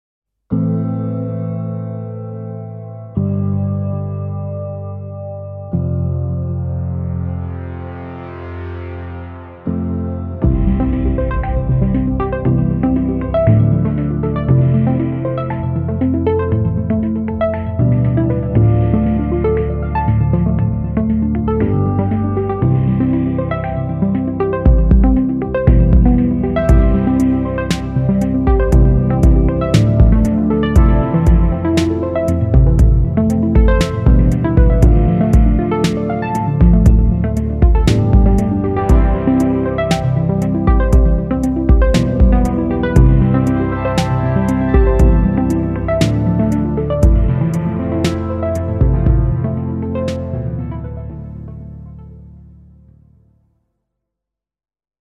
Largo [0-10] melancolie - ensemble instruments - - -